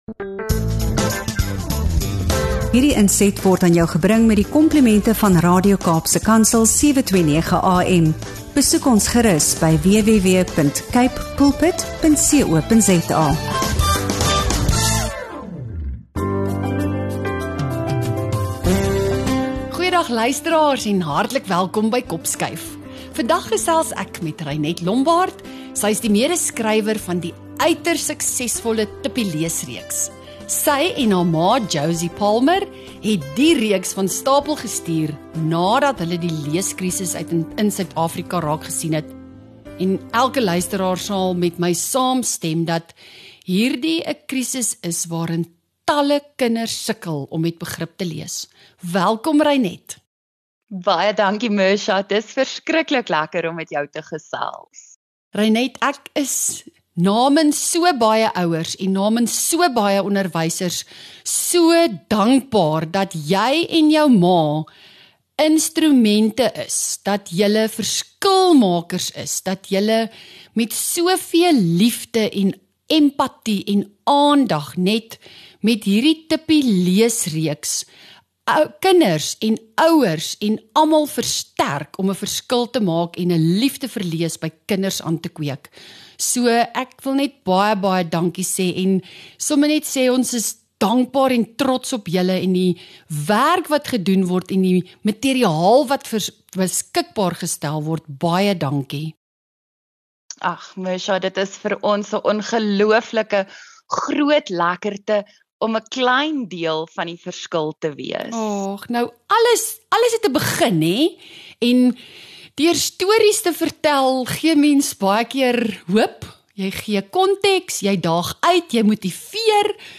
In hierdie insiggewende gesprek hoor jy meer oor die ATKV-EkKanLees-projek, die ontwikkeling van sterk geletterdheidsvaardighede, en praktiese hulpmiddels wat ouers en onderwysers kan gebruik om ’n ondersteunende, verrykende en interessante lees- en leeromgewing vir kinders te skep.